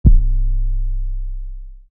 BackDown808_YC.wav